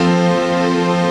HOUSPAD09.wav